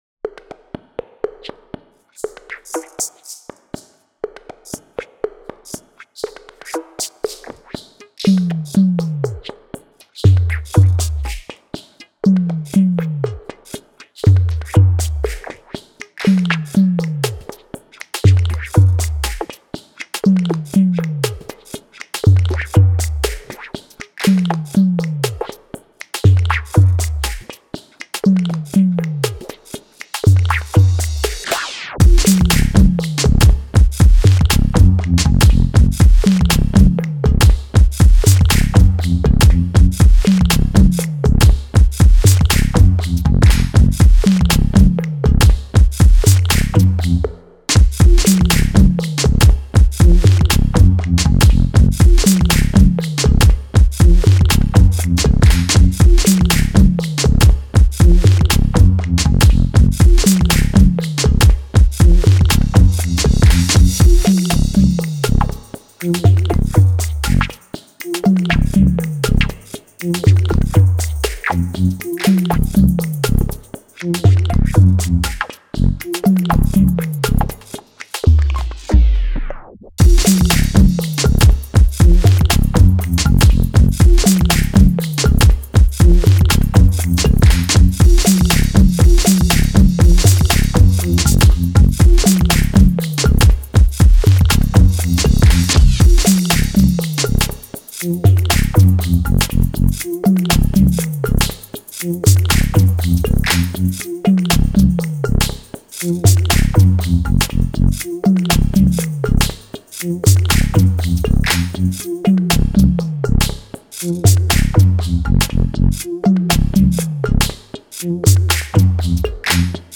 Sluggish, yet forward moving beat with peculiar bassline.